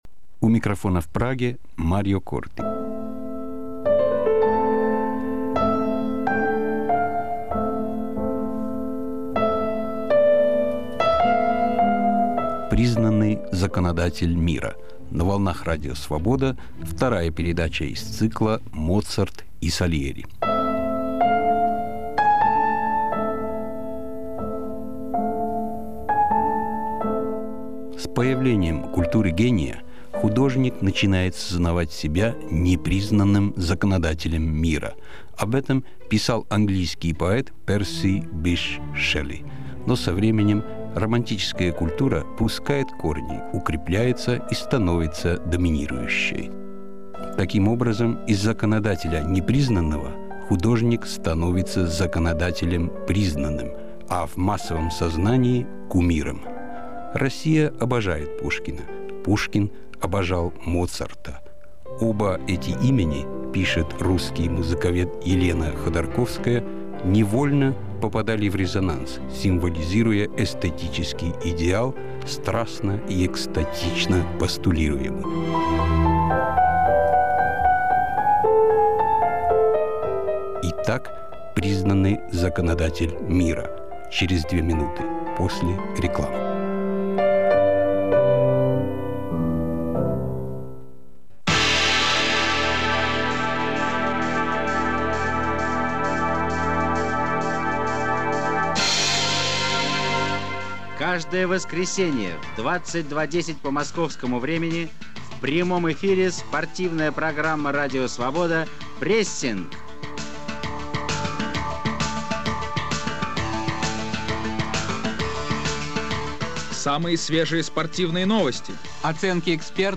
Моцарт и Сальери. Историко-музыкальный цикл из девяти передач, 1997 год.